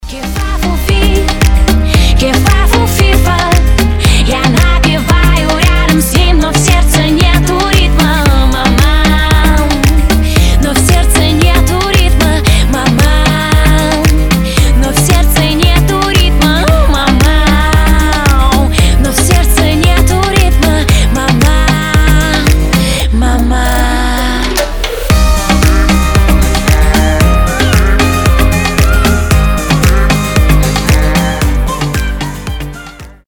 • Качество: 320, Stereo
поп
веселые